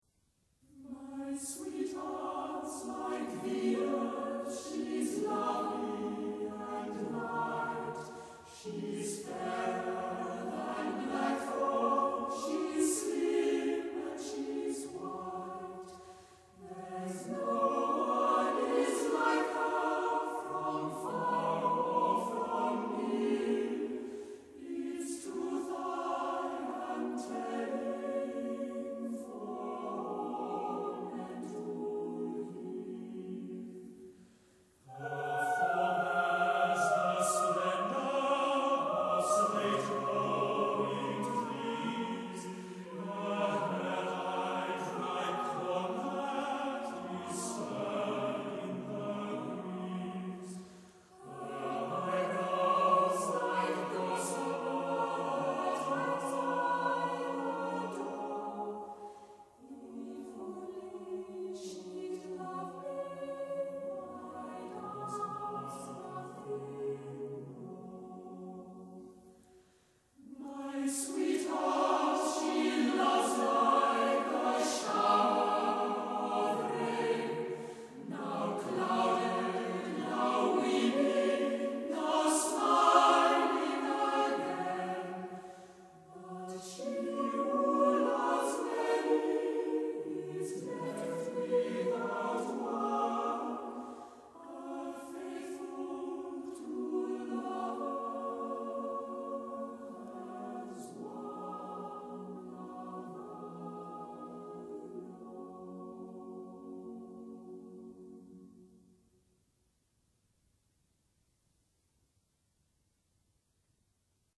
My Sweetheart’s Like Venus by The Cambridge Singers (Classical Choral)